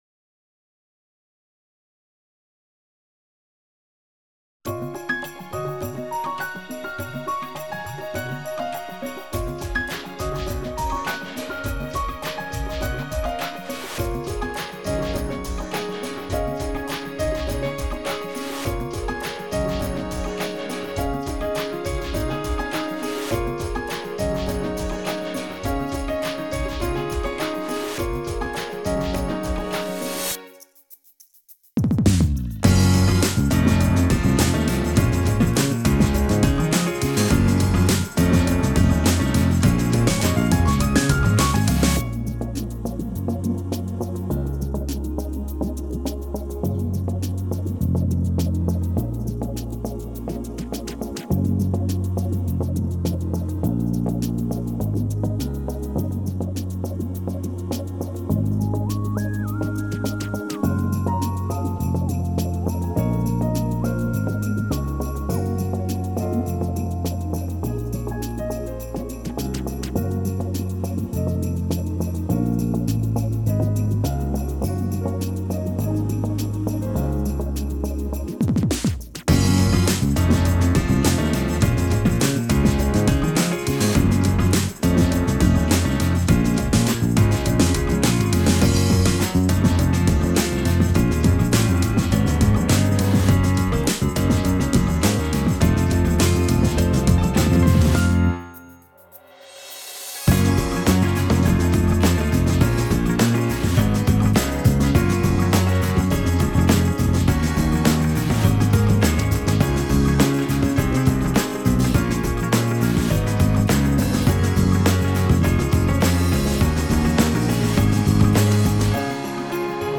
オリジナルKey：「C#